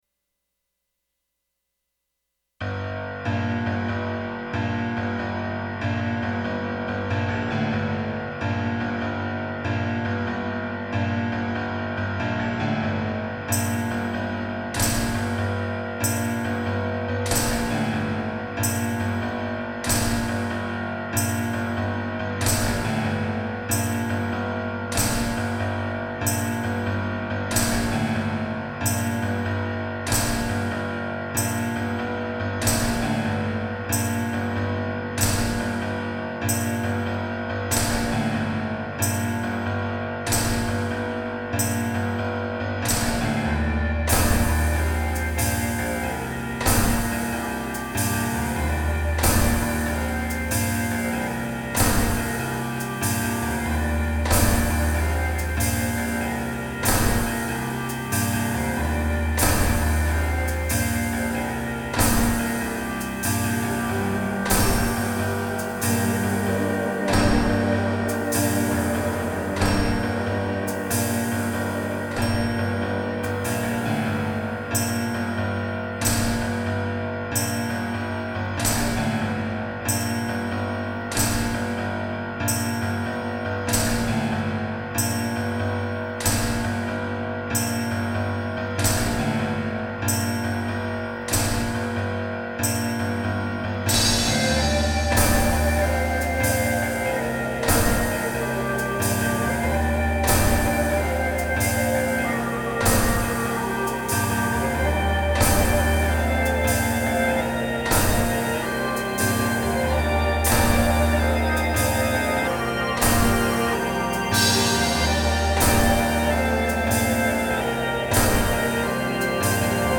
Haven't recorded vocals yet, so I know the song itself sounds a little repetitive without them, but I need some objective feedback because I've been listening to this song for 3 days straight!